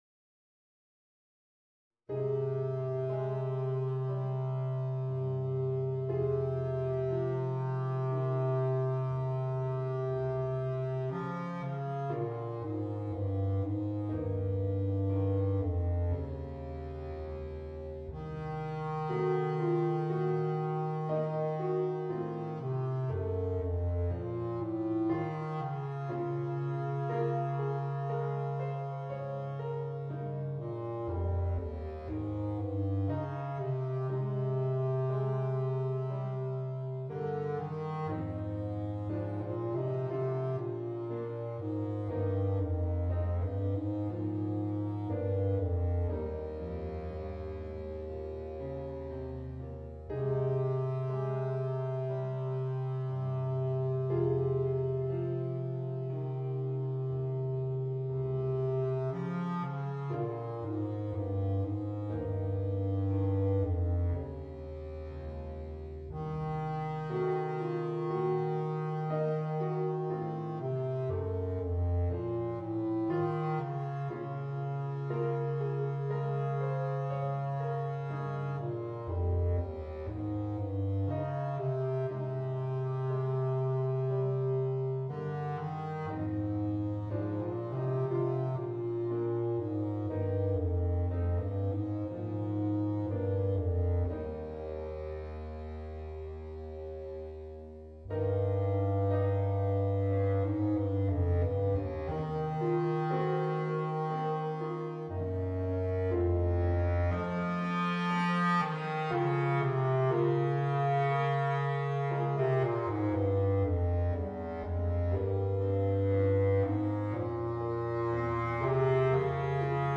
Voicing: Bass Clarinet and Organ